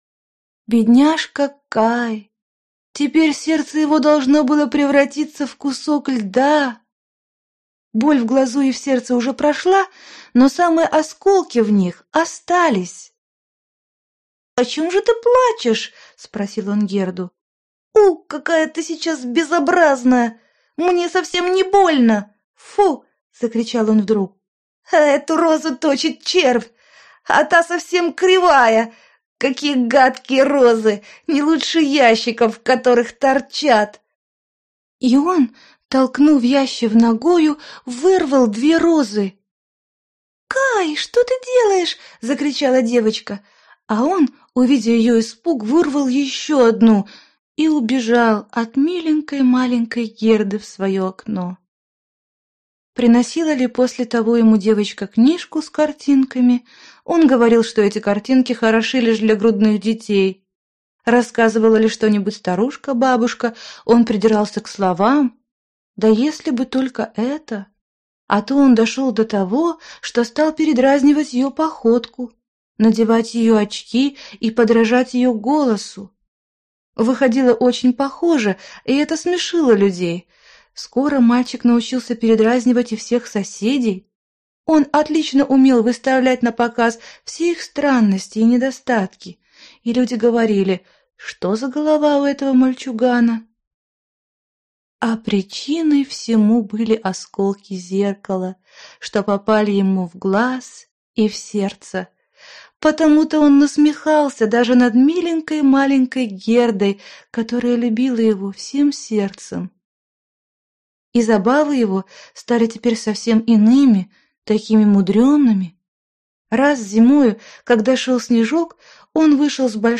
Аудиокнига Сложный литературный английский. Диск 11 | Библиотека аудиокниг